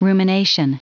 Prononciation du mot rumination en anglais (fichier audio)
Prononciation du mot : rumination